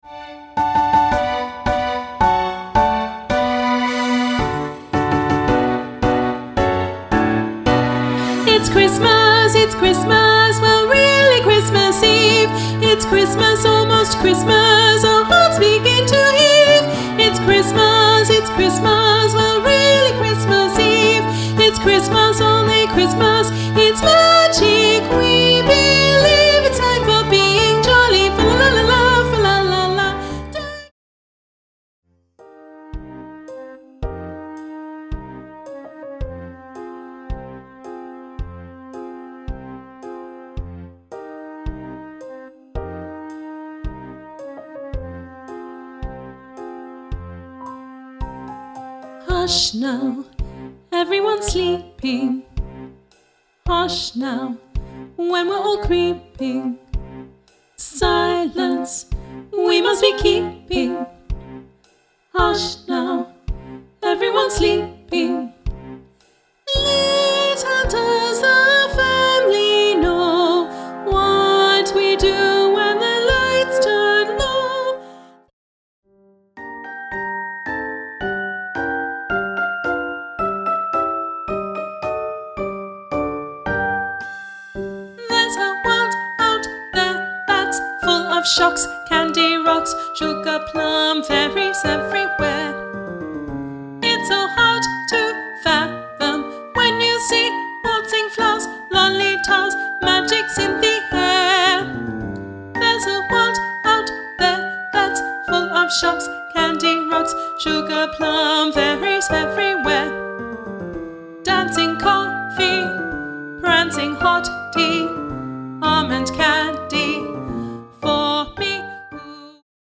Australian choral interpretation
Genre : 'Classical'-4 pieces Suitable for